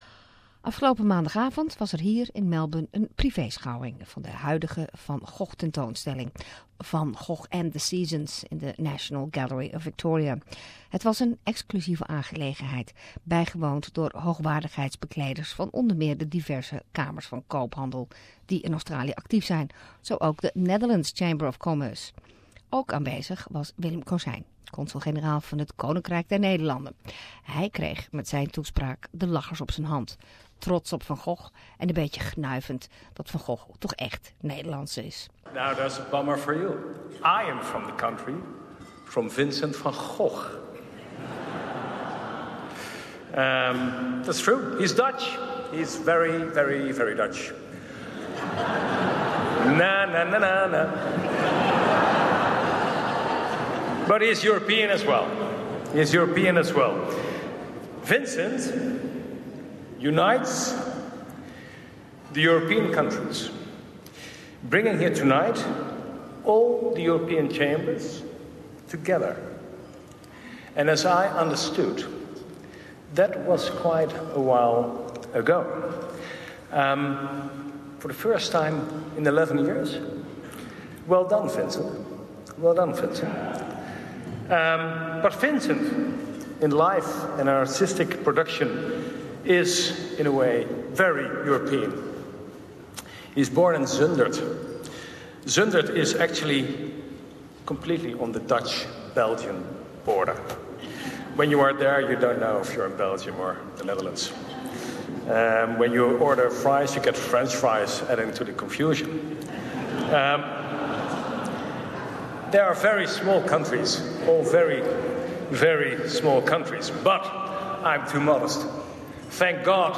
During a private showing of the ;Van Gogh and the Seasons' expo in the NGV Consul, General Willem Cosijn managed to get some giggles during his speech.